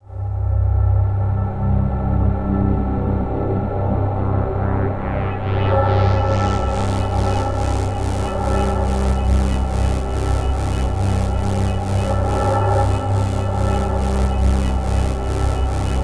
SWEEP07   -L.wav